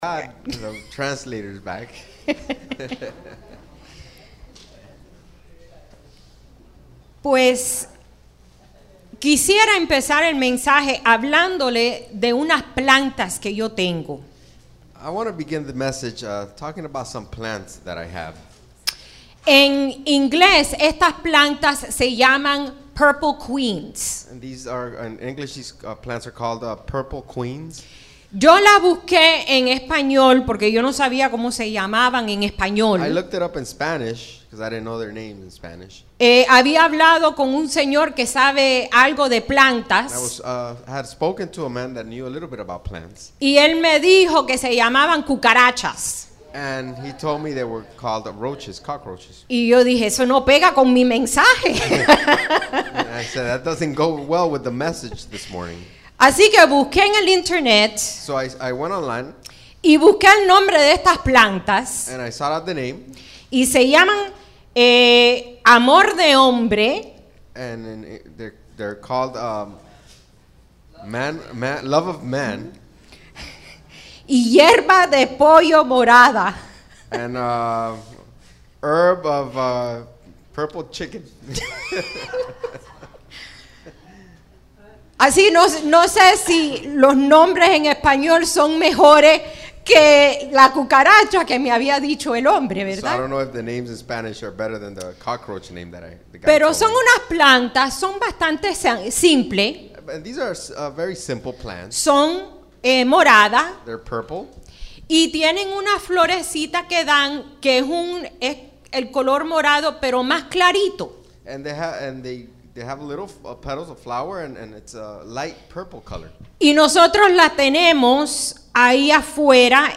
Sermons | Iglesia Centro Evangelico